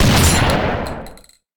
rifle.ogg